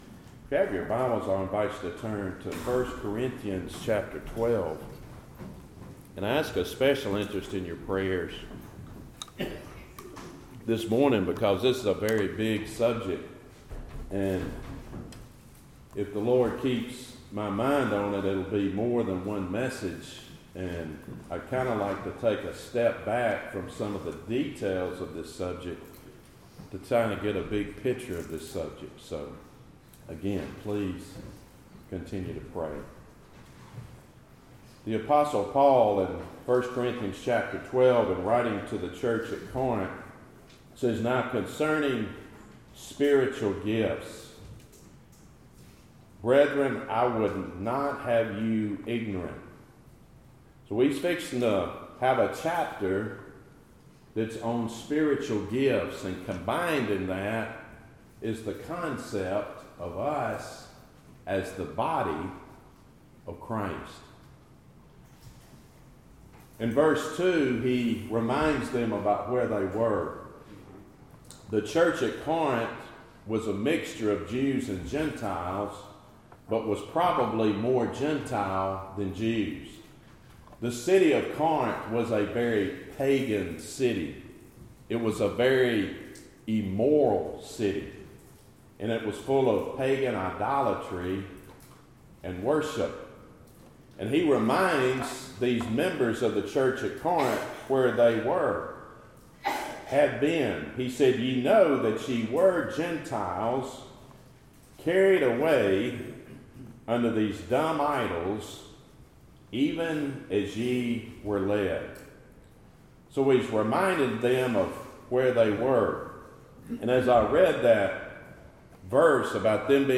Series: Gifts in the Church Topic: Sermons